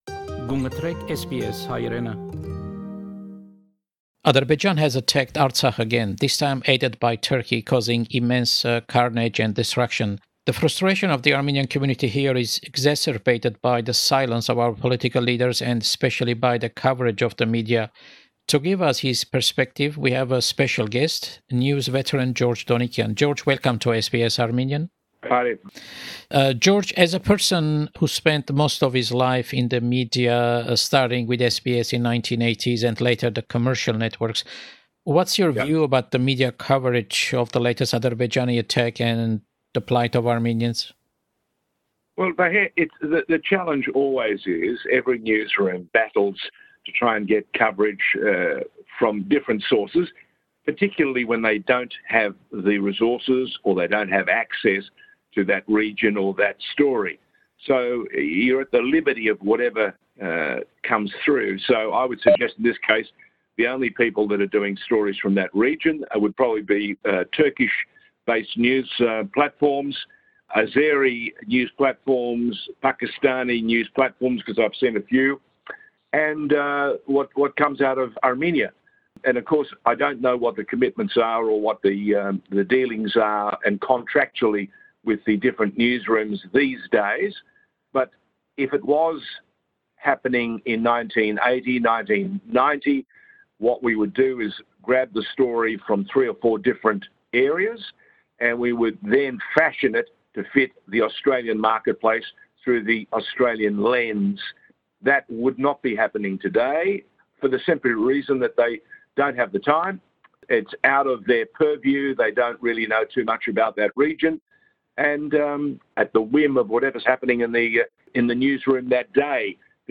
Interview with news veteran George Donikian. Topics of the interview include the misinformation in the media when covering the Azerbaijani/Turkish attack on Artsakh (Karabagh) and the lockdown in Melbourne.